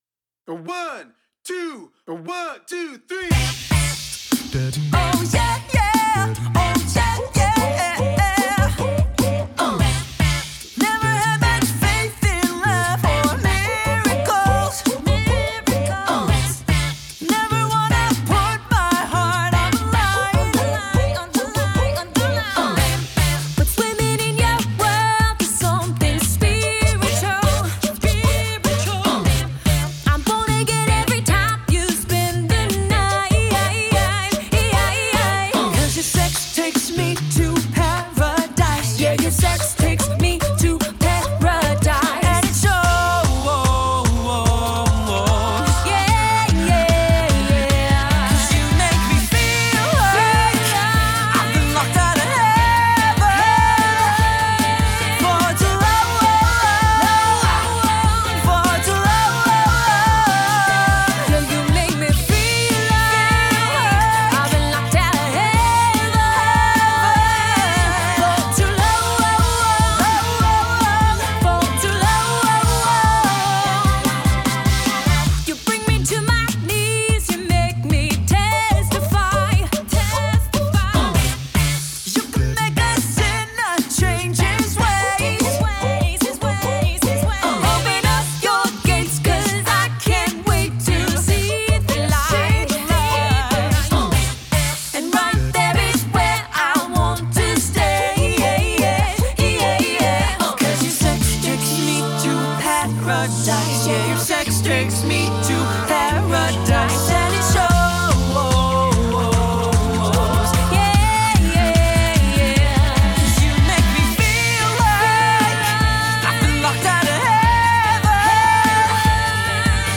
Genre: Pop, Classical